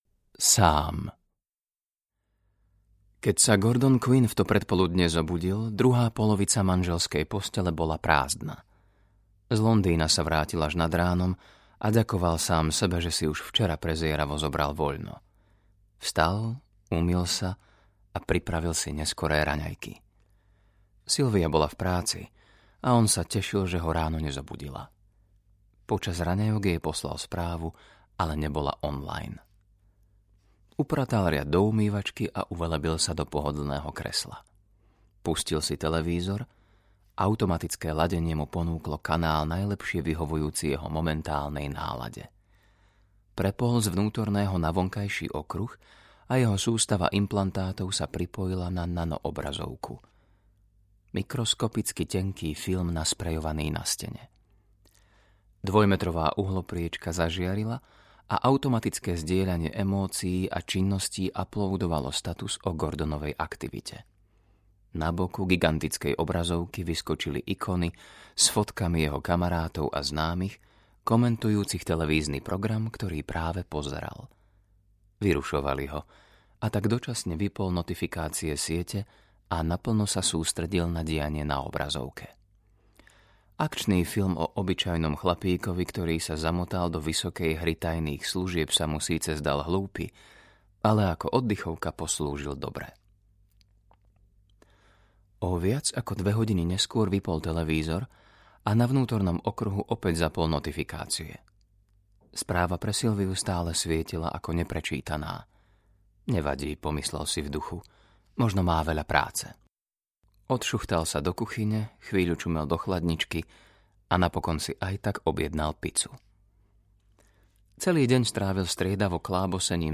3x sci-fi audiokniha
Ukázka z knihy